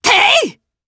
Demia-Vox_Attack4_jp_b.wav